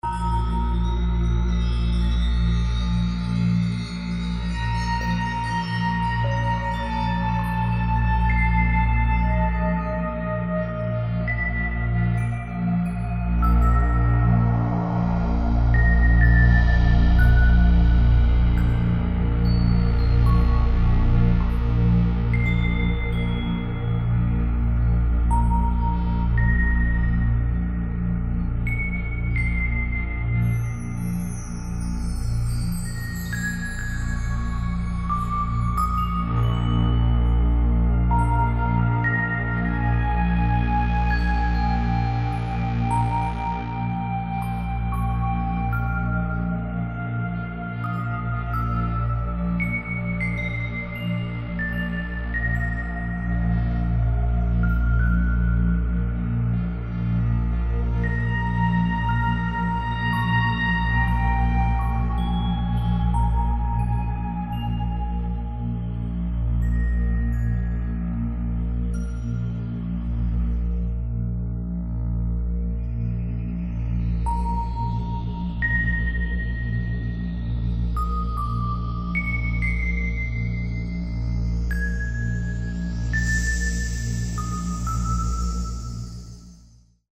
Ambient spacey track